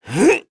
Crow-Vox_Jump.wav